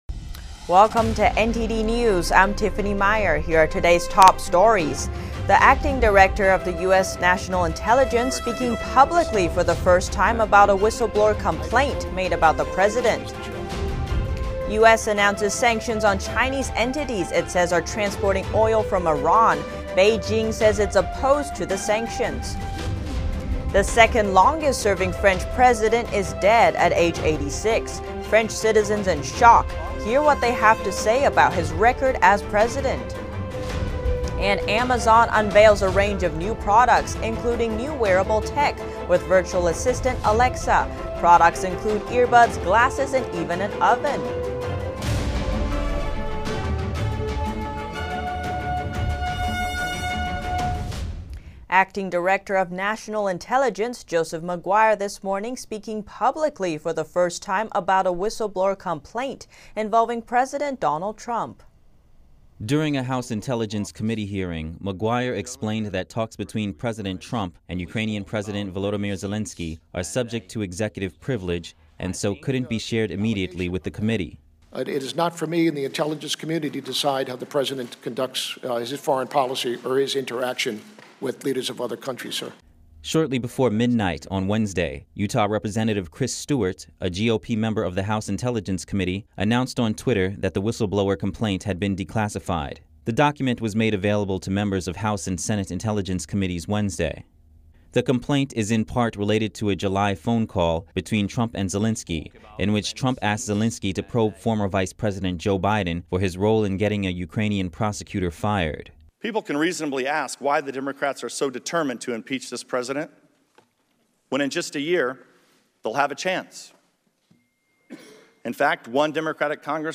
Full Broadcast